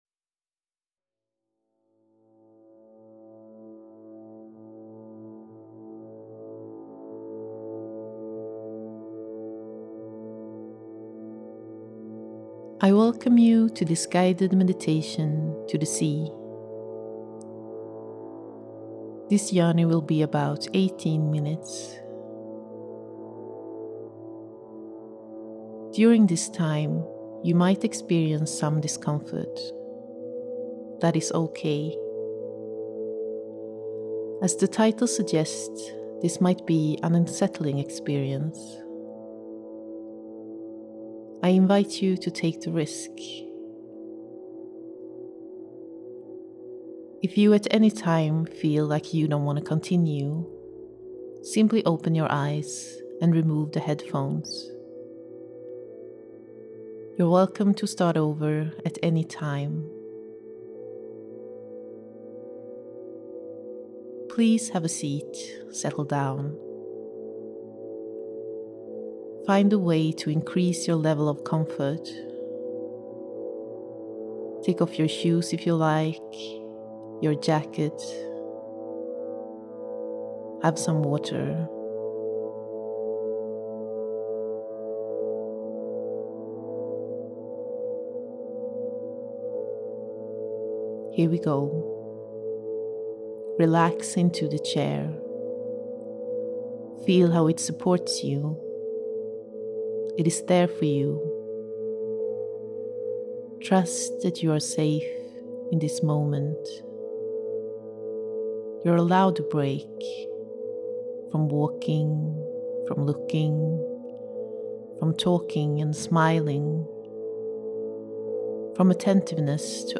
The work deals with unsettling and de-centering by the means of meditation. A voice guides the listener through an awareness exercise, where one’s body is soaked into the sea, and the rhythm of one’s body is disrupted by a call from the deep. There is an element of ”soft cohesion”, a persuasiveness of the voice, guiding the listener to dispossess of the current reality, falling into another rhythm, to be possessed and unsettled.
Sound installation, 18 min (Beach chair, headphones, button)